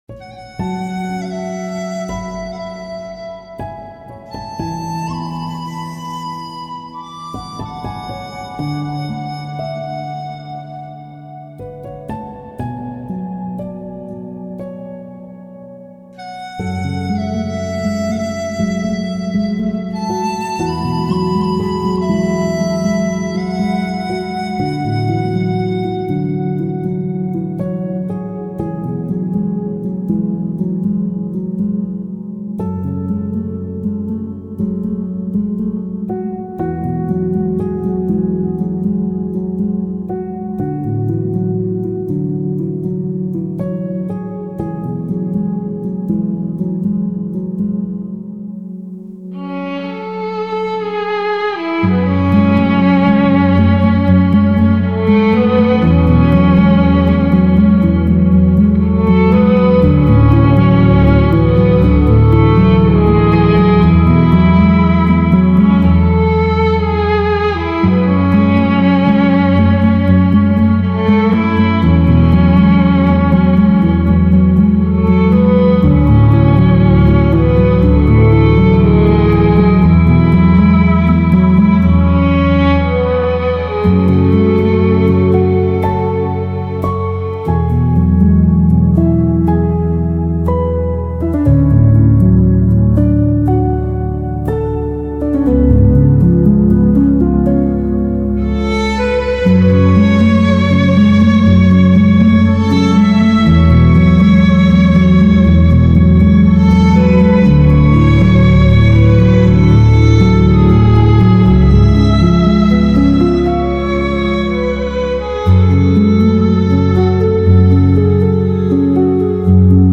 موسیقی بی کلام آرامش بخش عصر جدید
موسیقی بی کلام فانتزی